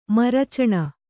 ♪ maru caṇa